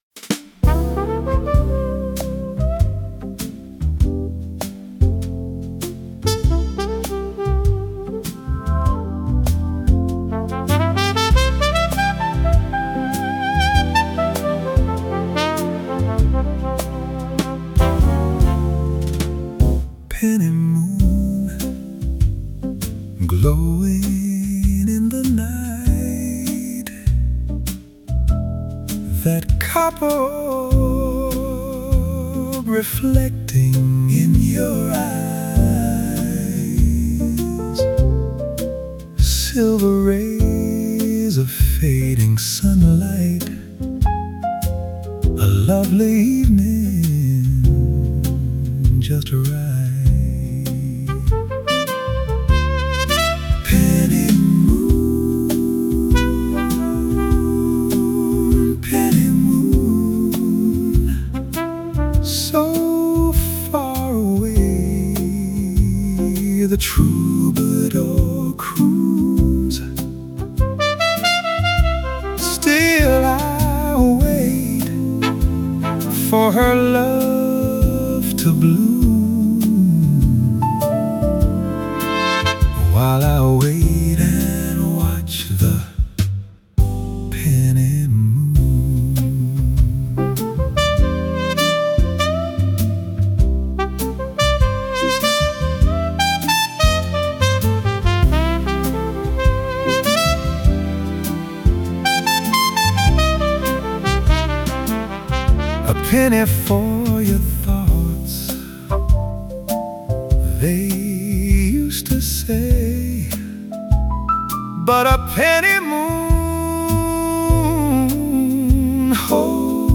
If you enjoyed this little 1930’s style tune, please leave a comment below.